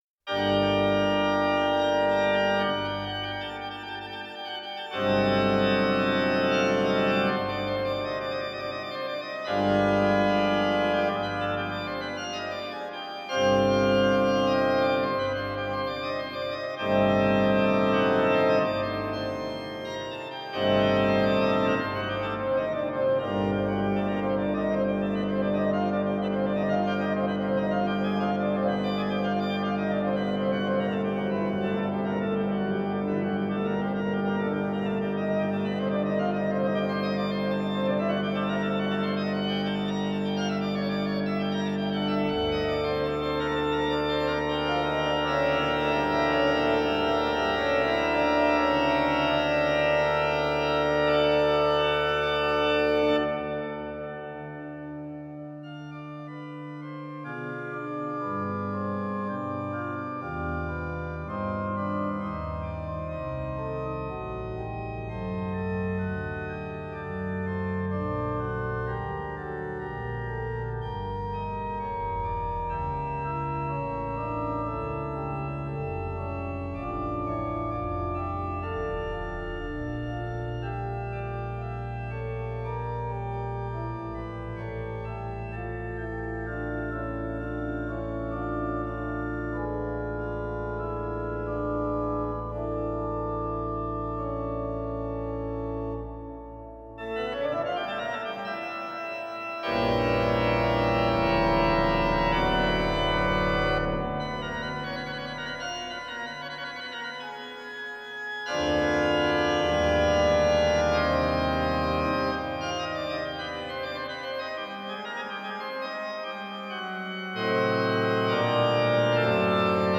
Except for percussion stops, all voices of this organ were created with additive synthesis, tonally matching spectral analysis
The soundfont contains no recorded organ sounds.
Click here to listen to Bach’s Fantasia in G Minor on the 4P/104 with convolution reverb (York Minster Cathedral).